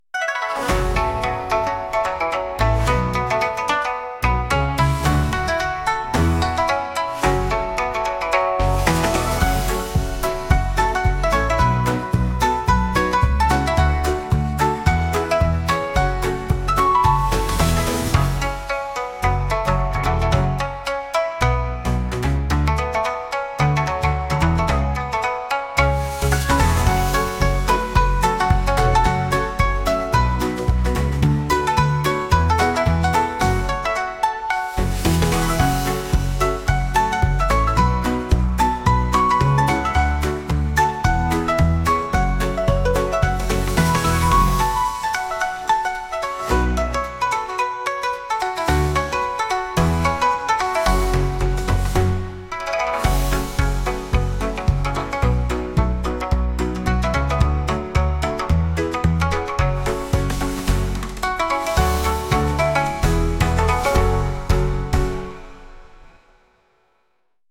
神社にお参りするような曲です。